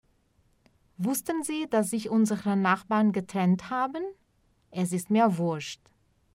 Veja agora, algumas frases com “Wurscht”, gíria usada no sul da Alemanha. Note aqui que a pronúncia é um pouco diferente. s antes de ch (sch) tem som de x.